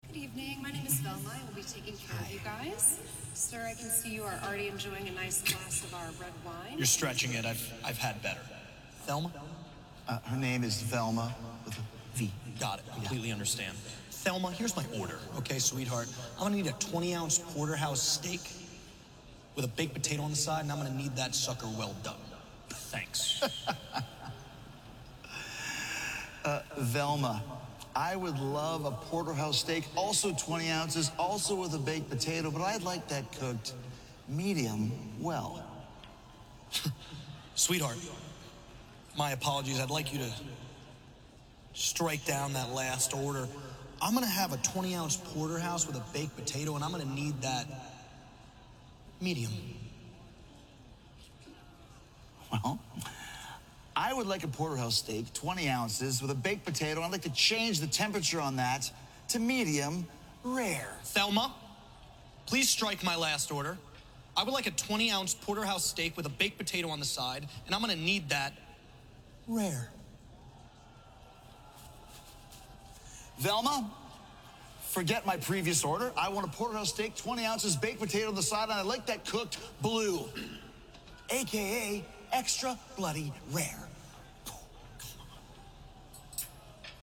Introduced by a Jim Ross who could not have possibly sounded more bored, we caught up with Jericho and MJF at a fine dining establishment.